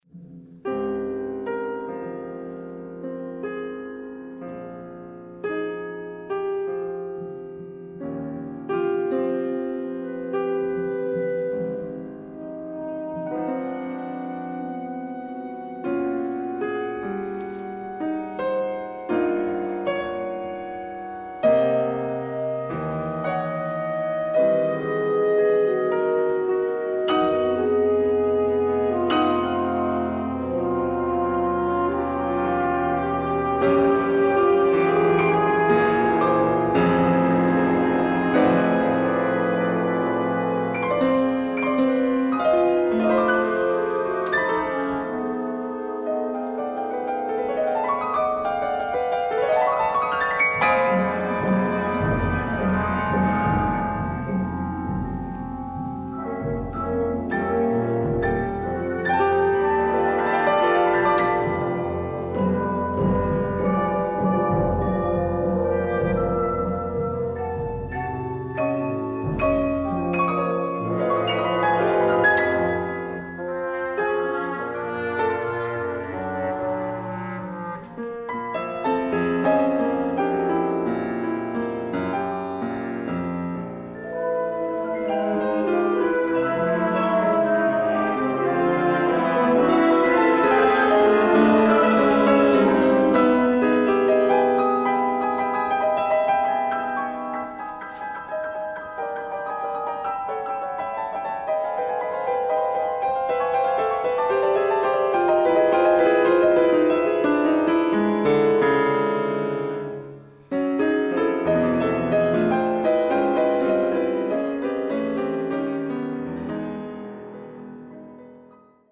Piano; harp; mallets I+II; perc.; windorchestra